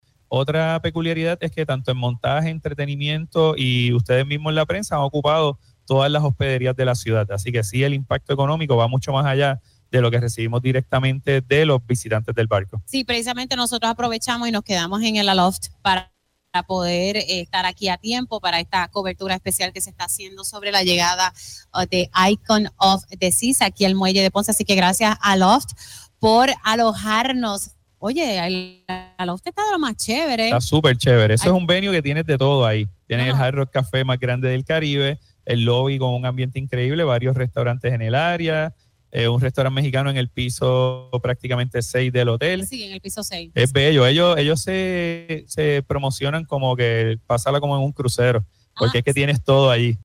Obviamente, estamos sin calcular, tenemos más de trescientos comerciantes recibiendo visitantes, no solamente del crucero, sino todos los curiosos y aficionados de los cruceros de toda la isla“, indicó en entrevista para Pega’os en la Mañana, en una transmisión en directo desde el puerto sureño para recibir a los pasajeros traídos por Royal Caribbean.